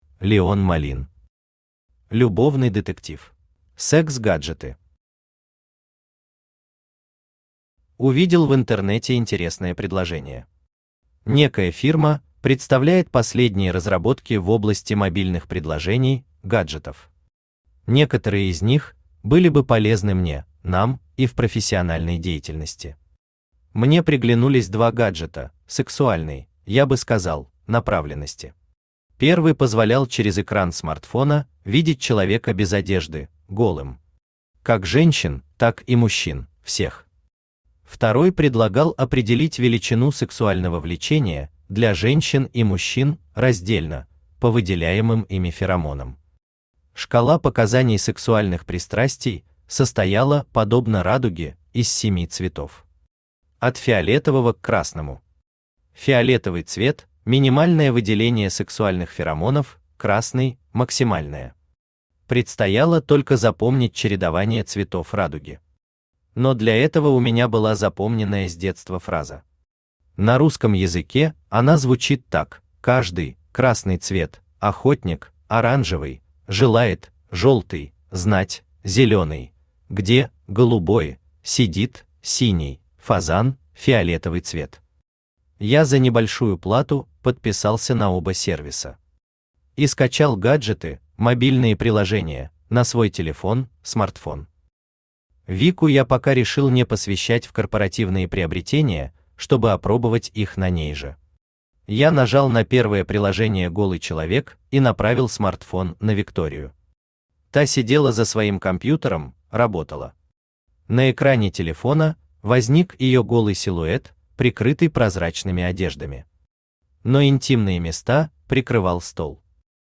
Аудиокнига Любовный детектив | Библиотека аудиокниг
Aудиокнига Любовный детектив Автор Леон Малин Читает аудиокнигу Авточтец ЛитРес.